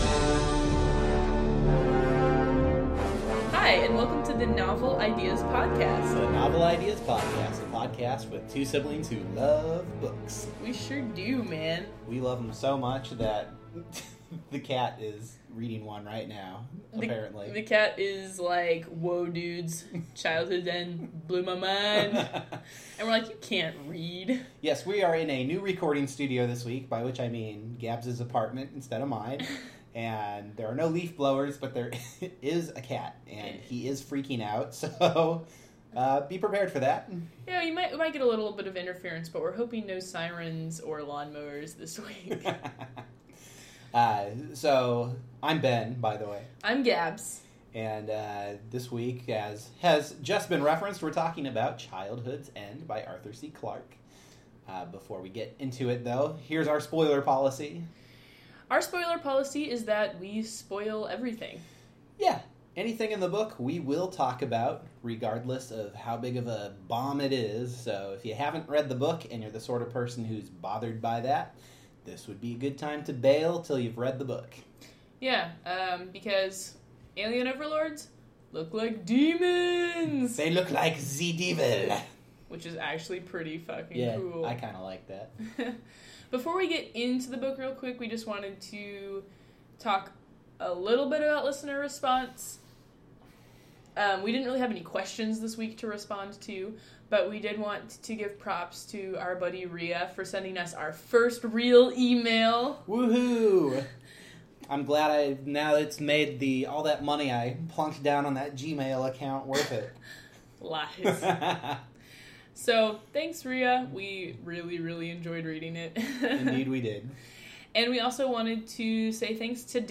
Our new recording studio (aka our “new” recording “studio”) is refreshingly free of sirens, barking dogs, and leafblower engines, but does neighbor a bird who badly wants to be a guest on the podcast.